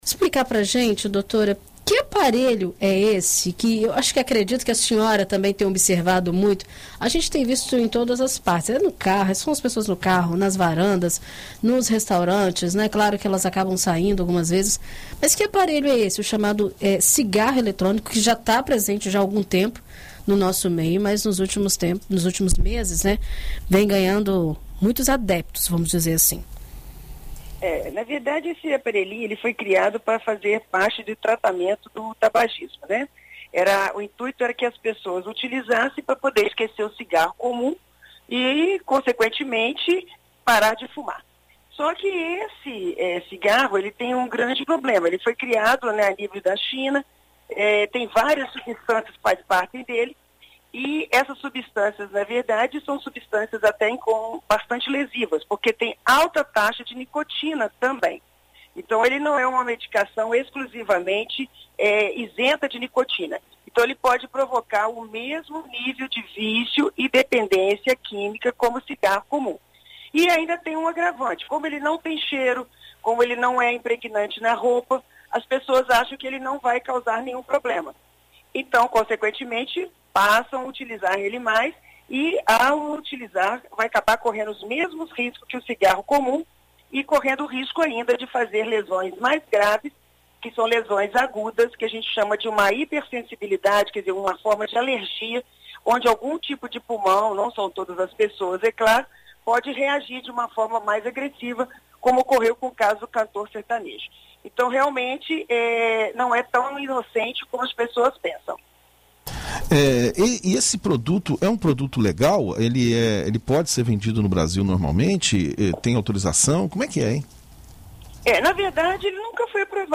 Em entrevista à BandNews FM Espírito Santo
ENT-PNEUMOLOGISTA.mp3